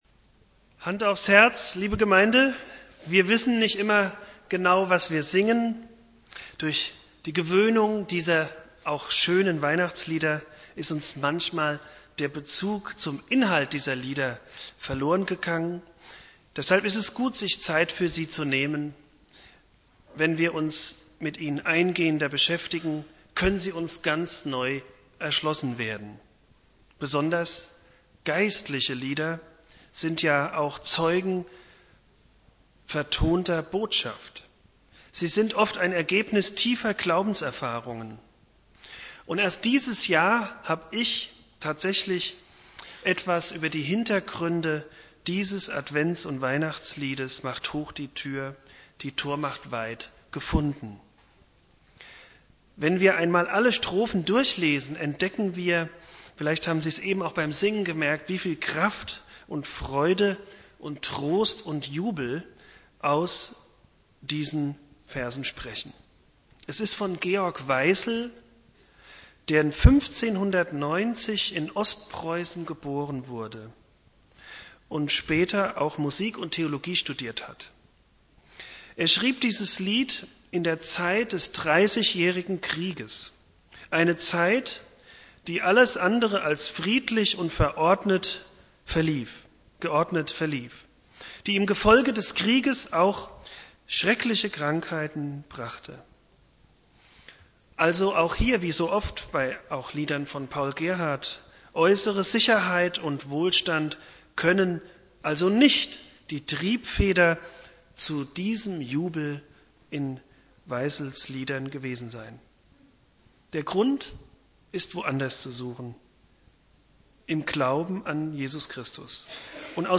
Predigt
1.Weihnachtstag